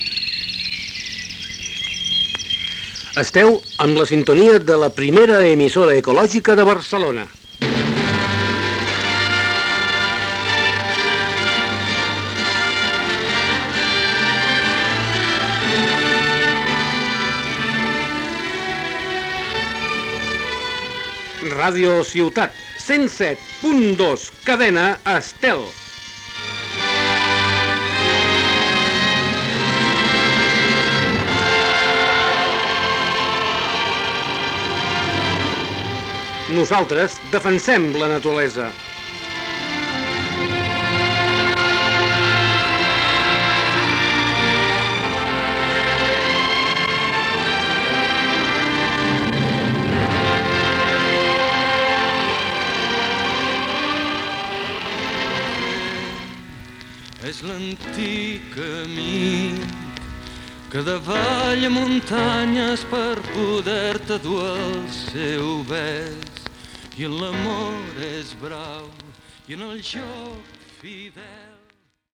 Identificació